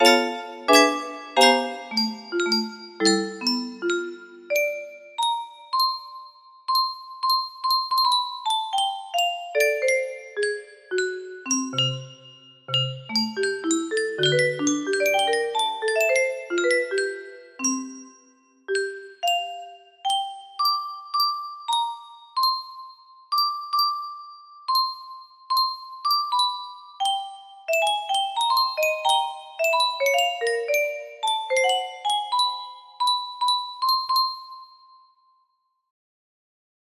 #2 music box melody